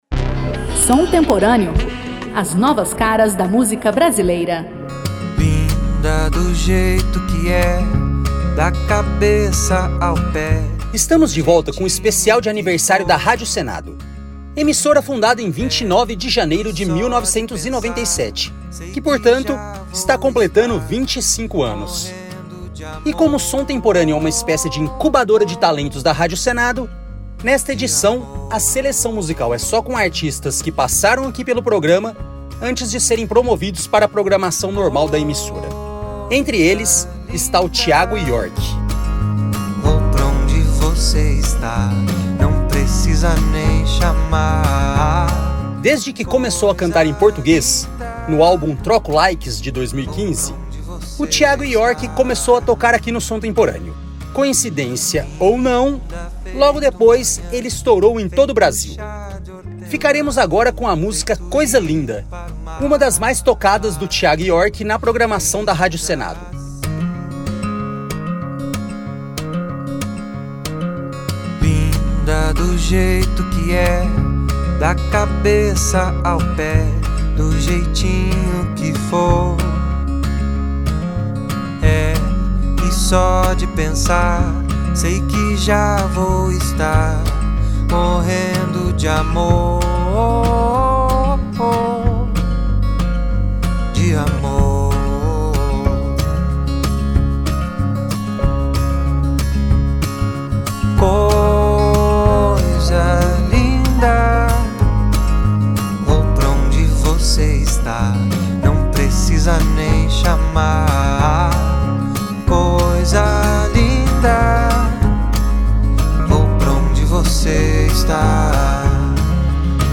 Música Brasileira